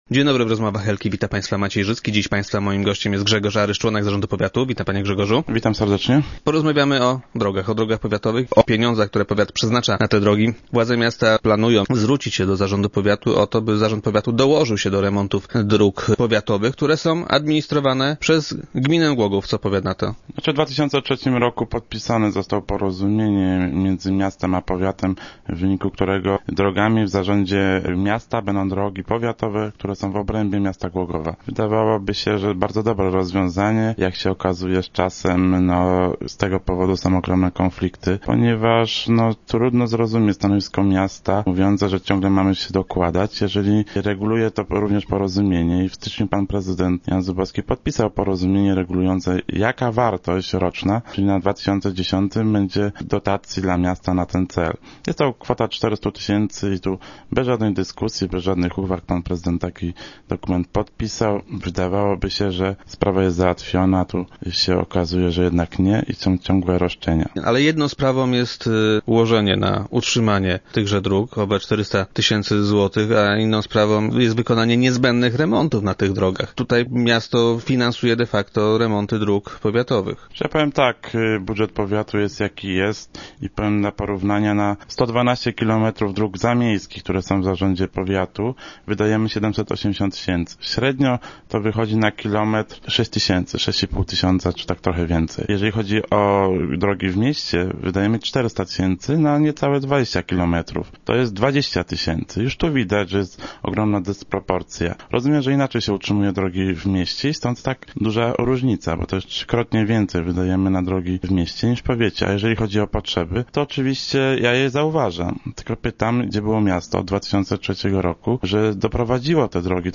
Zarząd powiatu twierdzi, że miasto powinno z powiatem ustalać zakres tych remontów. Jak powiedział podczas dzisiejszych Rozmów Elki Grzegorz Aryż, członek zarządu powiatu, może dobrym rozwiązaniem byłoby przejecie przez gminę powiatowych dróg.